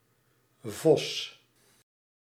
Ääntäminen
Ääntäminen Tuntematon aksentti: IPA: /vɔs/ Haettu sana löytyi näillä lähdekielillä: hollanti Käännös Konteksti Ääninäyte Substantiivit 1.